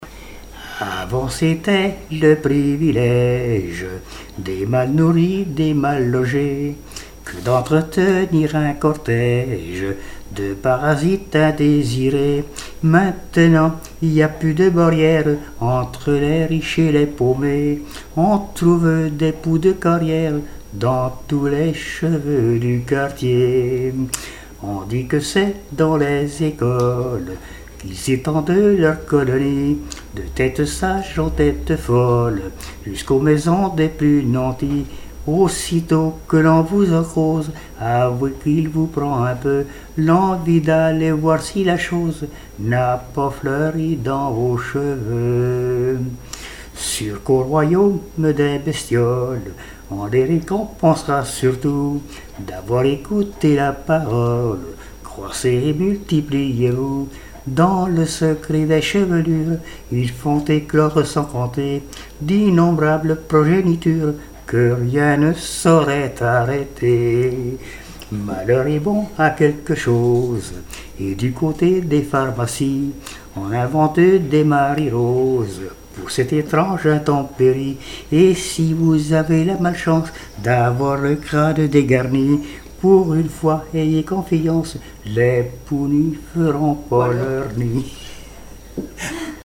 timbre de La Paimpolaise
chansons et témoignages
Pièce musicale inédite